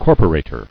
[cor·po·ra·tor]